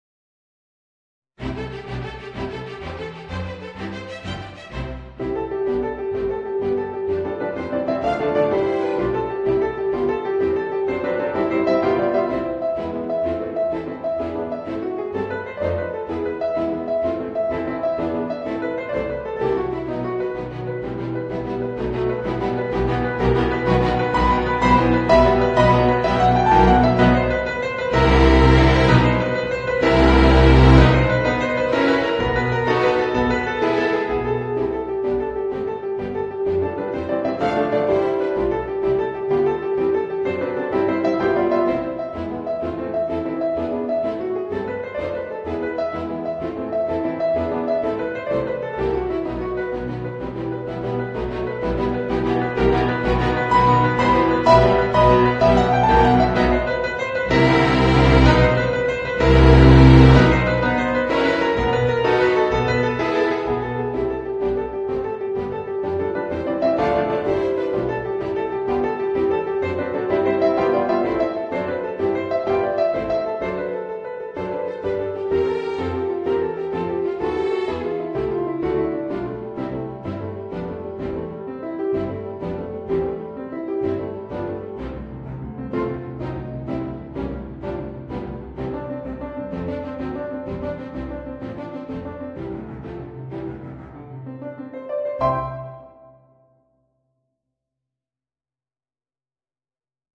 Voicing: Piano and String Orchestra